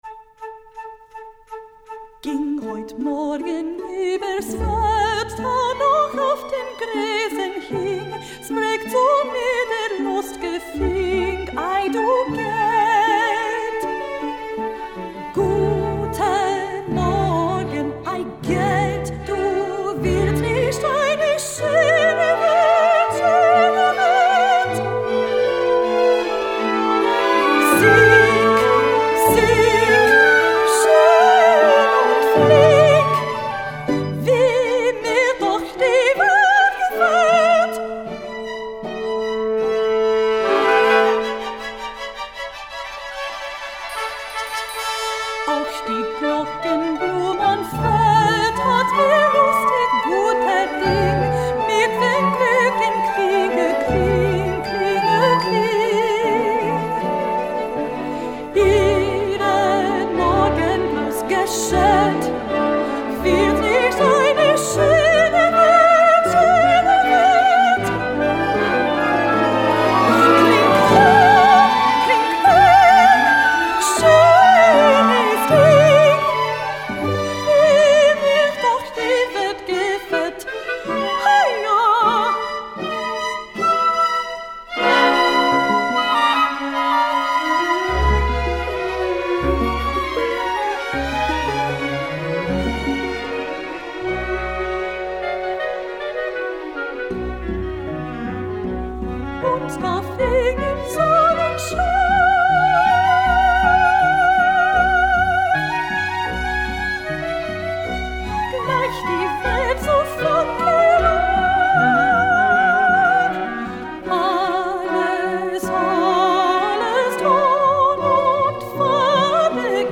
Voicing: String or Full Orchestra